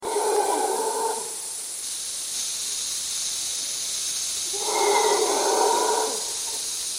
Wen oder was hört man in folgender Aufnahme? Konkret geht es um das Geräusch am Anfang und Ende. Das Grillenzirpen dazwischen (übrigens extrem laut hier und hat teilweise Konzertcharakter) ist nicht gemeint. 😉
Klingt wie Brüllaffen.
Es ist ein Brüllaffe (klick).